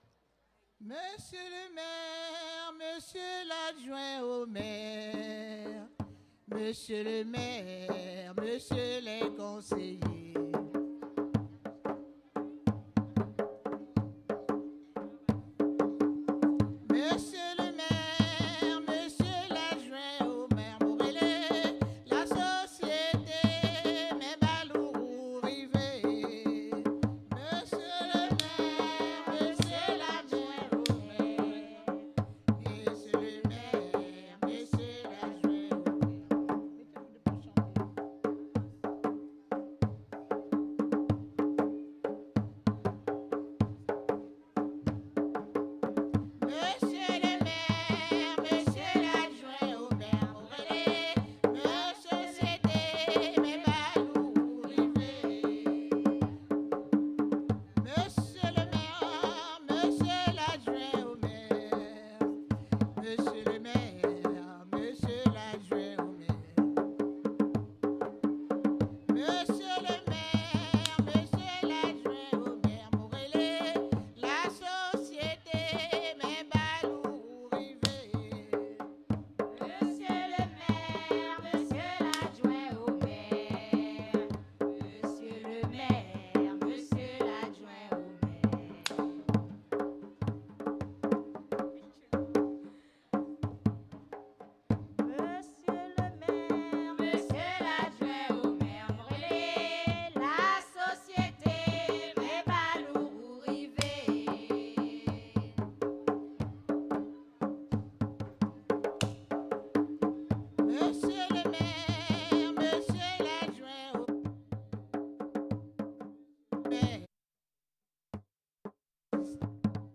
Soirée Mémorial
Chant d'entrée et de présentation du groupe
danse : grajé (créole)
Pièce musicale inédite